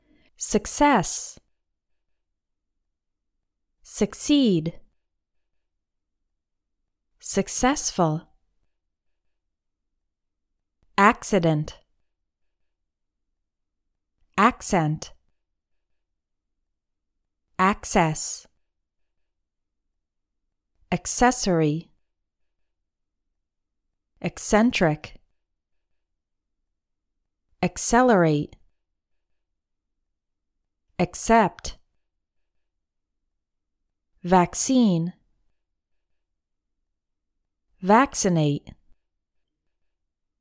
Pronounce CC like KS
The first way to pronounce CC is like KS.